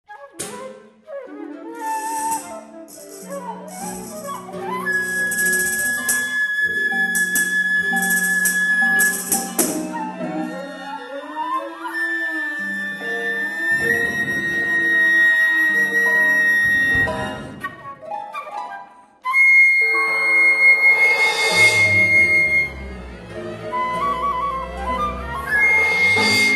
Australian, Classical